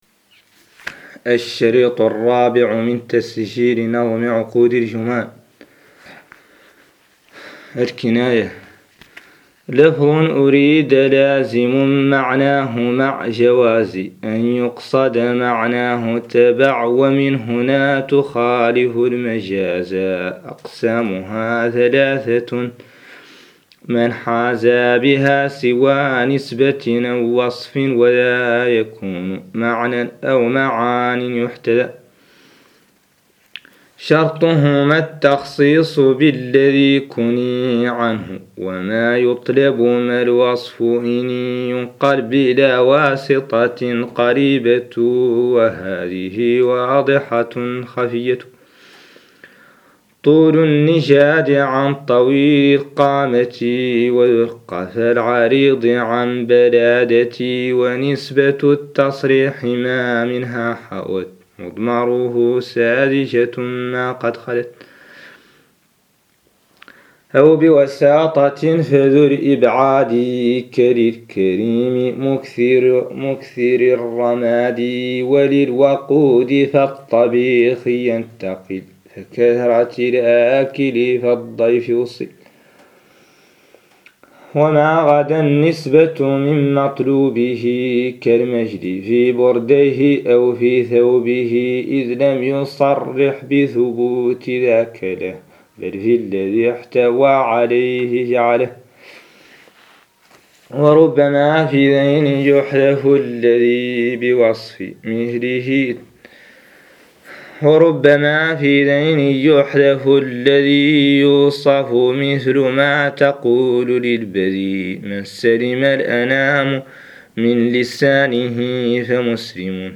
قراءة نظم عقود الجمان
ouqood-Juman-Reading04.MP3